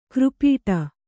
♪ křpīṭa